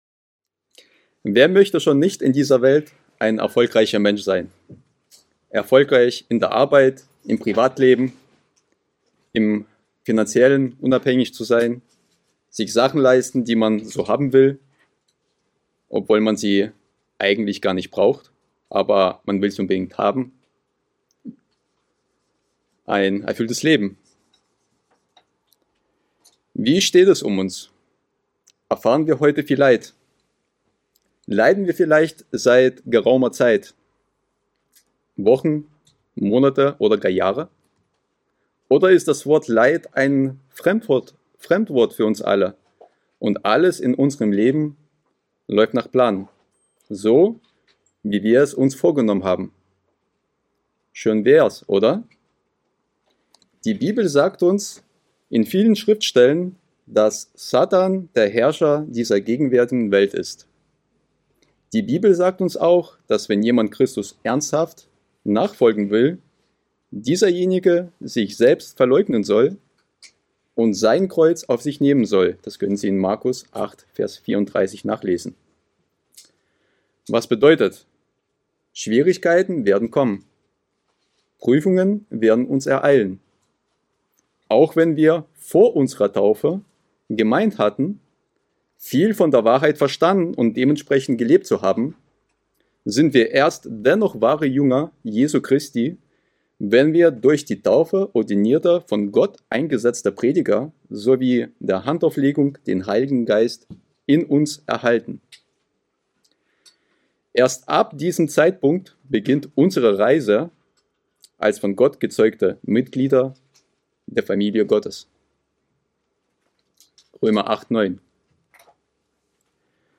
Doch auch wenn wir den Weg der Gerechtigkeit gehen, werden wir Leid erfahren und ja, sogar erfahren müssen. Diese Kurzpredigt geht darauf ein, warum das der Fall ist, und warum es sogar für uns notwendig ist zu leiden, um erfolgreich in unserem Leben zu sein und um schlussendlich ein wiedergeborenes Mitglied der Gott Familie zu werden.